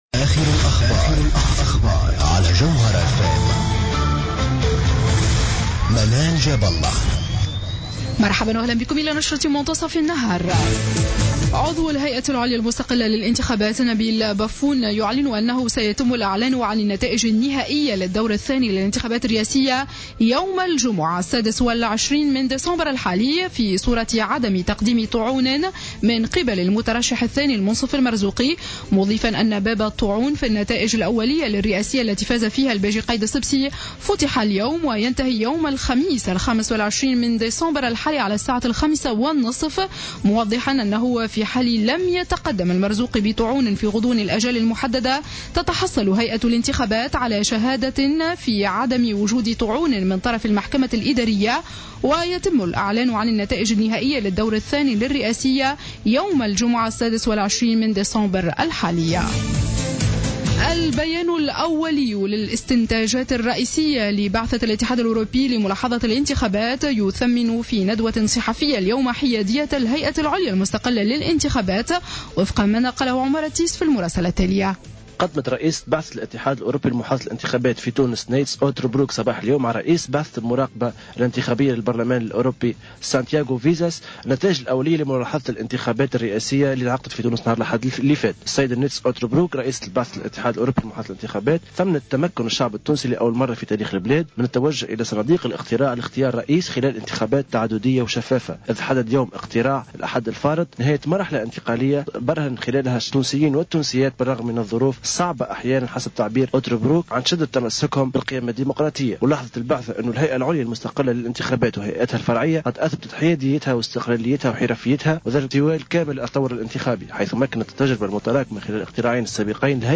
نشرة أخبار منتصف النهار ليوم الثلاثاء 23-12-14